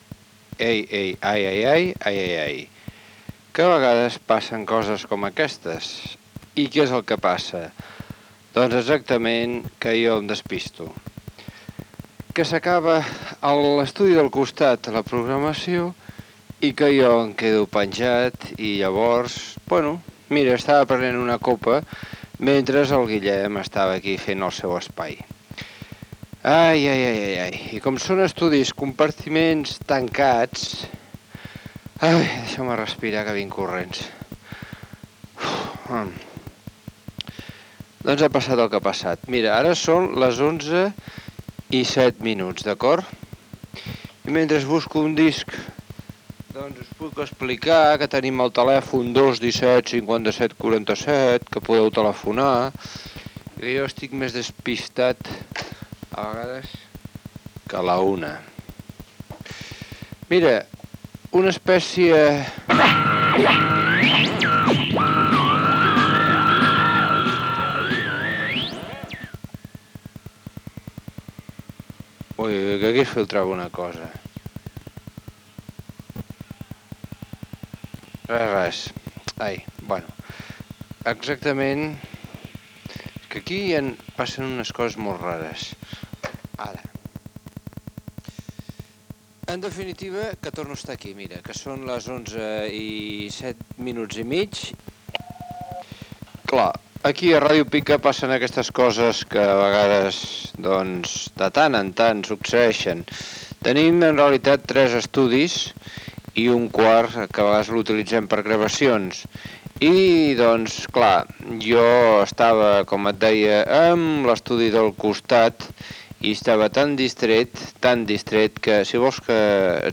25d5217d237014a3127e2b1f3dce1564ae4f5aa8.mp3 Títol Ràdio Pica Emissora Ràdio Pica Titularitat Tercer sector Tercer sector Lliure Descripció Disculpes per arribar just al directe, explicació i comentari sobre un espai emès pel matí.